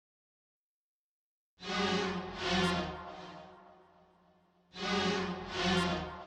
描述：E 阿拉伯语音阶
Tag: 131 bpm Trap Loops Synth Loops 2.47 MB wav Key : E